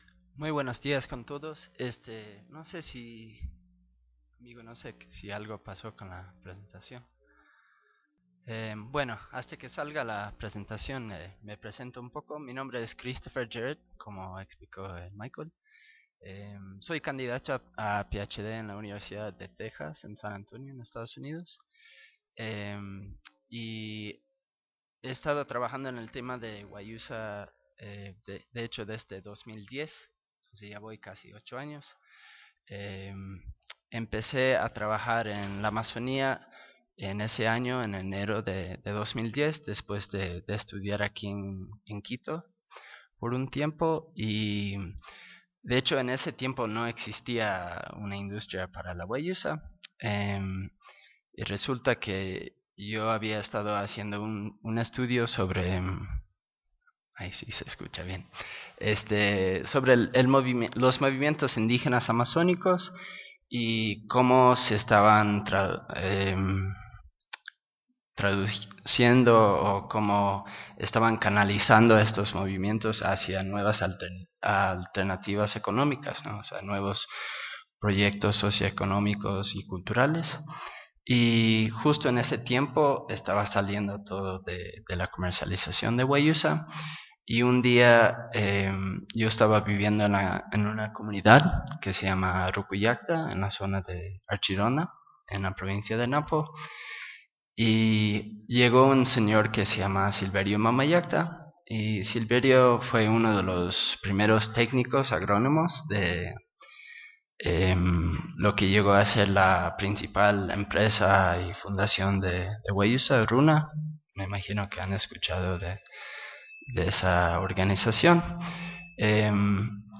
en el marco del Coloquio ‘Cosmología, alteridad y globalización’
Conversatorio